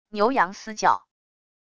牛羊嘶叫wav音频